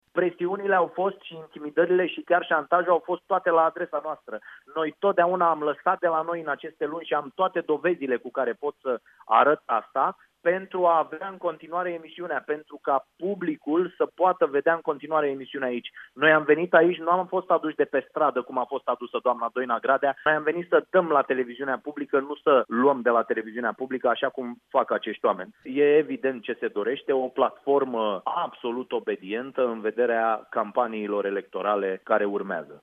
Iată ce spune într-un interviu la Europa FM jurnalistul Dragoș Pătraru, după trecerea șefei TVR prin Parlament: